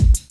Wu-RZA-Kick 11.WAV